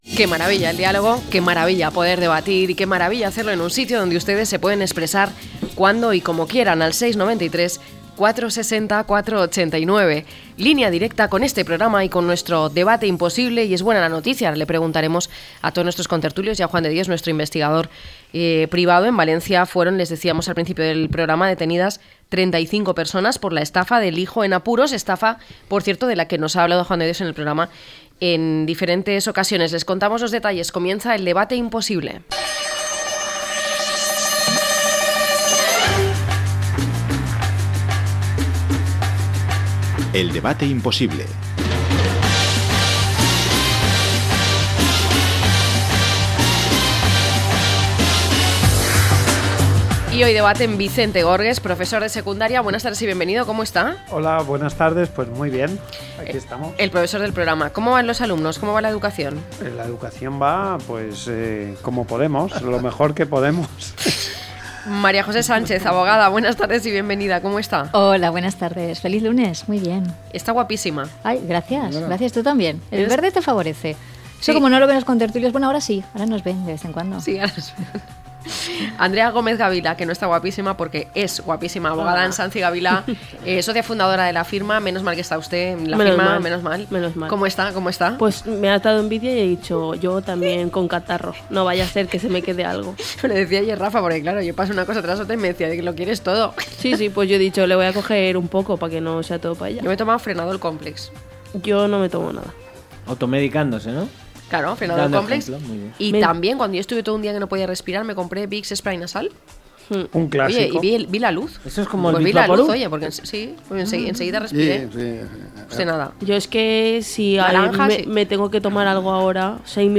1028-LTCM-DEBATE.mp3